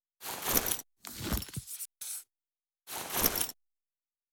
打开笔记放入背包.wav